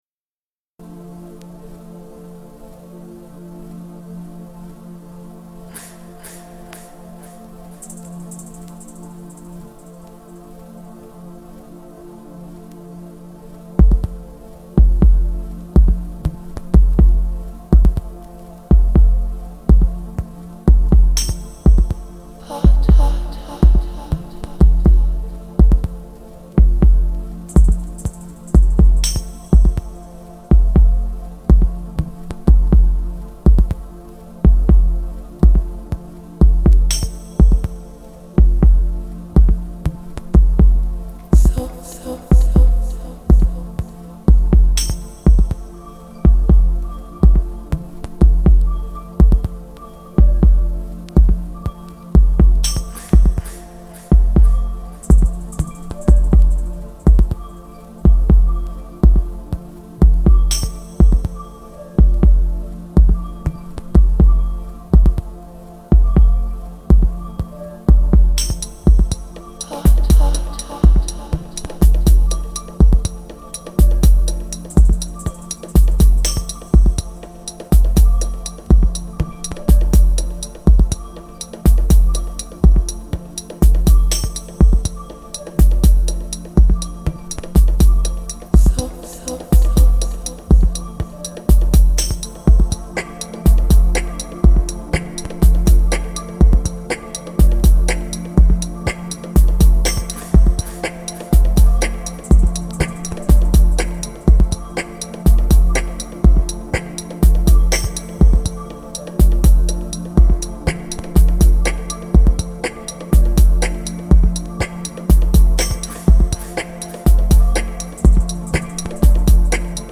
spanning an hour of hypnotic, sub-heavy house & techno.